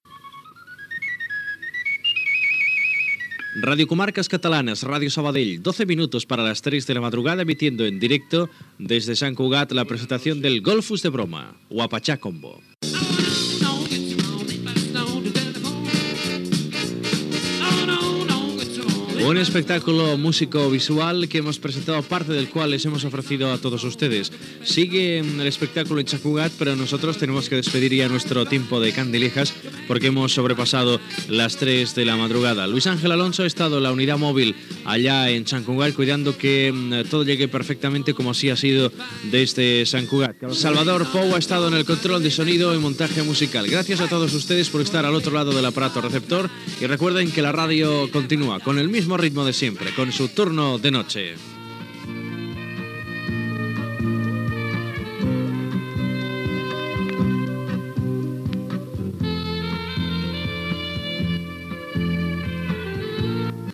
Identificació de l'emissora, hora, transmissió des de Sant Cugat del Vallès de la presentació del disc 'Golfus de Broma' del grup Huapachá Combo, comiat del programa amb els noms de l'equip
Presentador/a